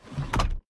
DrawerClose.mp3